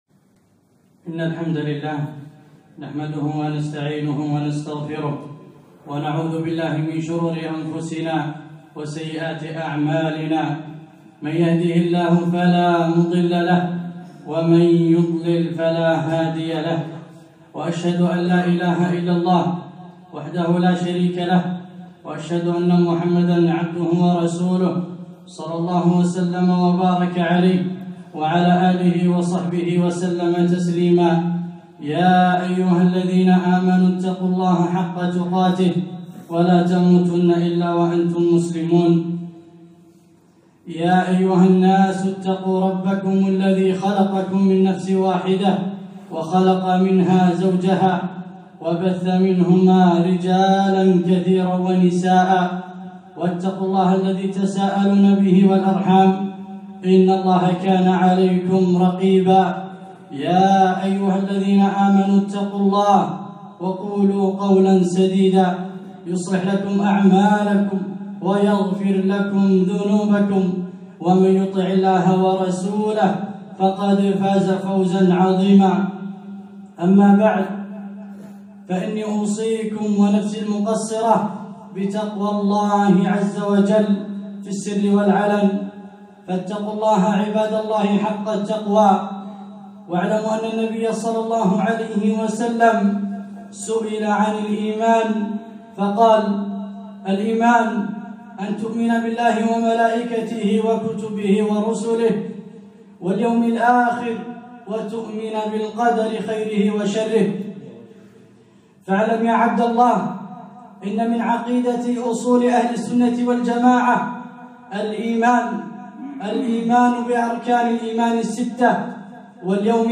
خطبة - اليوم الاخر